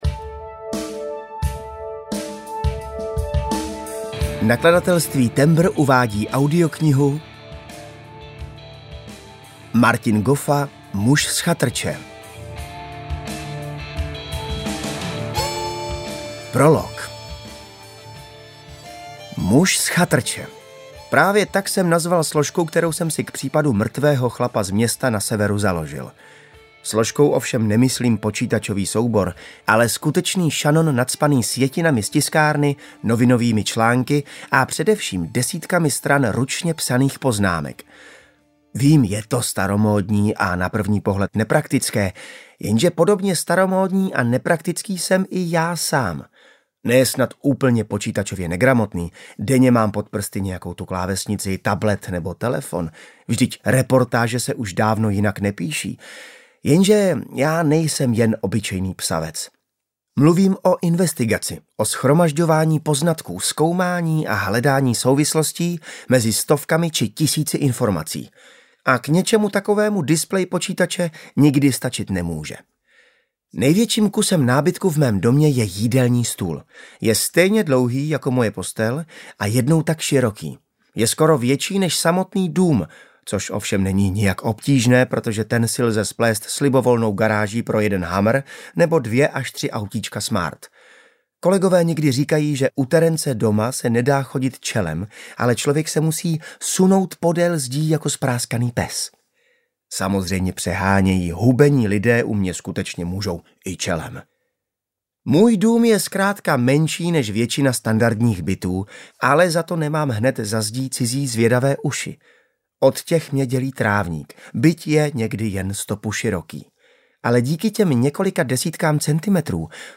Muž z chatrče audiokniha
Ukázka z knihy
muz-z-chatrce-audiokniha